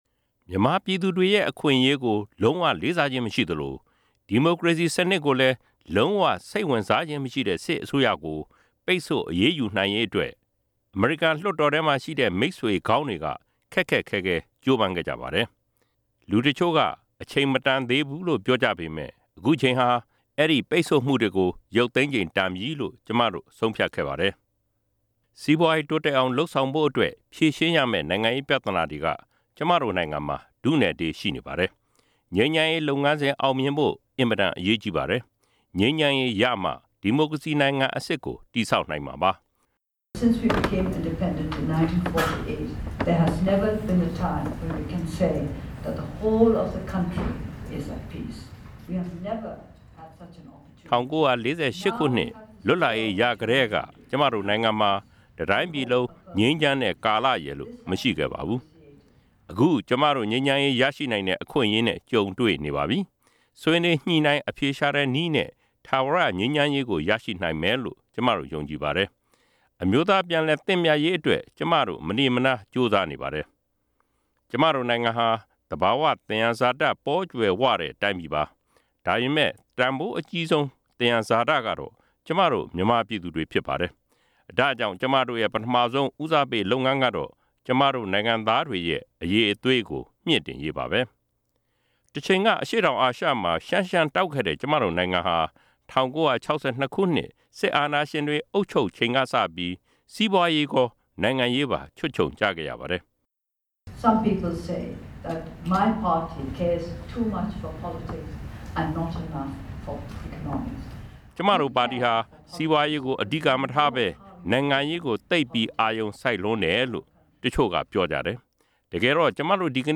အမေရိကန်အာဆီယံစီးပွားရေးကောင်စီ ညစာစားပွဲ ဒေါ်အောင်ဆန်းစုကြည်မိန့်ခွန်းကောက်နှုတ်ချက်
ဝါရှင်တန် ဒီစီမြို့တော်ကိုရောက်ရှိနေတဲ့ နိုင်ငံတော်အတိုင်ပင်ခံပုဂ္ဂိုလ် ဒေါ်အောင်ဆန်းစုကြည်ဟာ စက်တင်ဘာ ၁၅ ရက် မနေ့ညနေက အမေရိကန် အာဆီယံ စီးပွားရေးကောင်စီနဲ့ အမေရိကန် ကုန်သည်ကြီးများအသင်းကကျင်းပတဲ့ ညစာစားပွဲမှာ မိန့်ခွန်းပြောကြားခဲ့ပါတယ်။